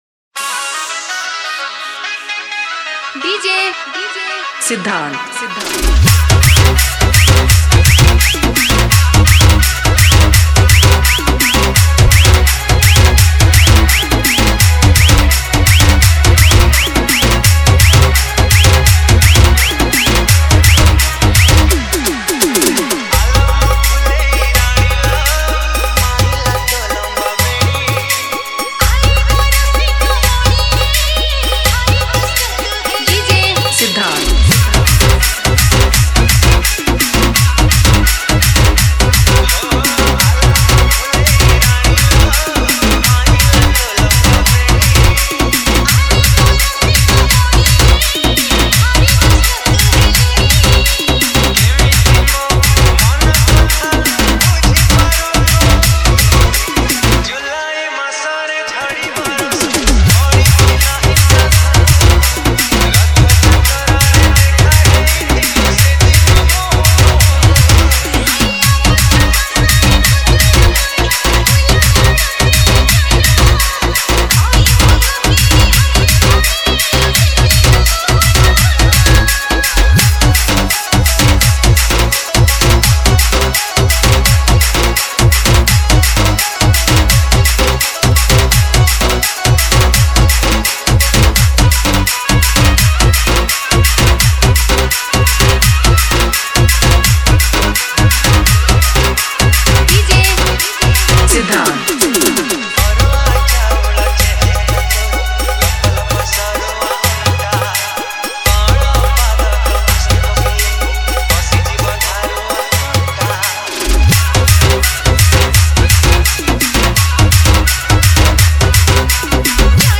ODIA LOVE DJ SONGS